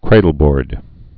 (krādl-bôrd)